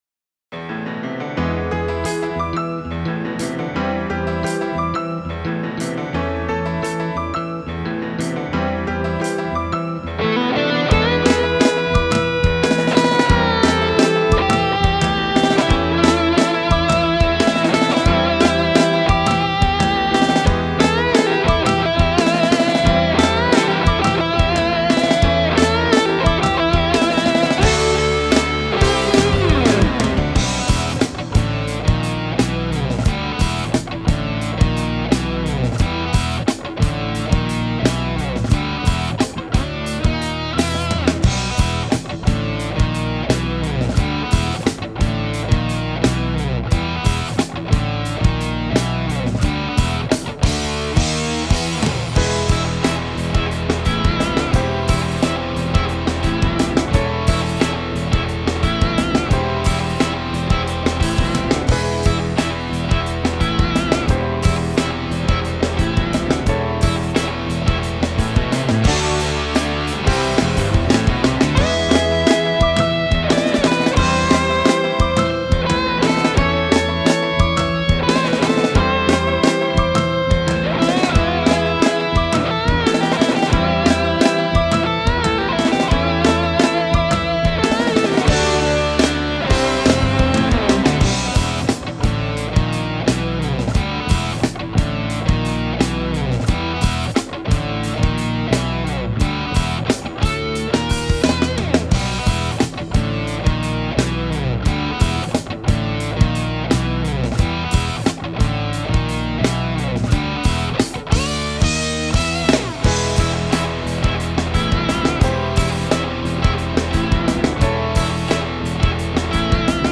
How do these re-amped guitar tracks sound?
As some of you know, I started experimenting with re-amping DI guitar tracks.
No vocals yet. This is just something I started working on yesterday, so it's also a pretty rough mix.